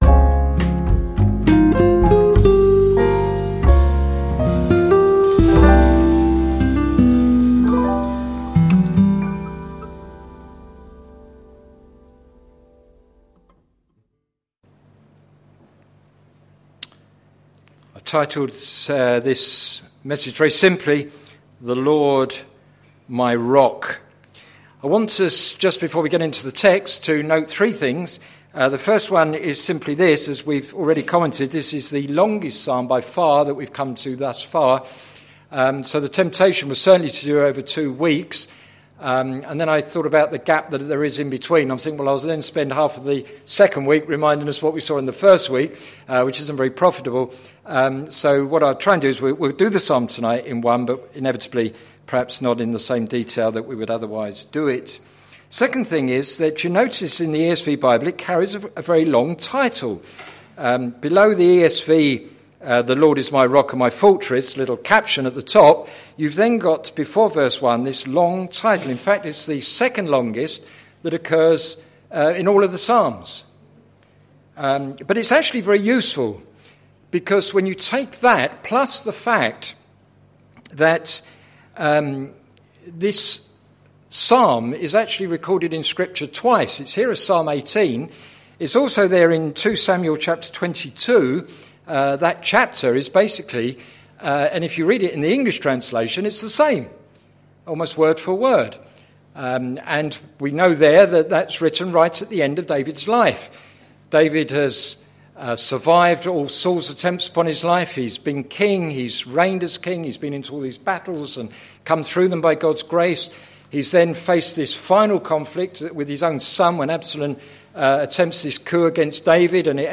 Sermon Series - Songs to live and songs to sing - plfc (Pound Lane Free Church, Isleham, Cambridgeshire)